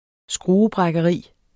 Udtale [ ˈsgʁuːəˌbʁagʌˌʁiˀ ]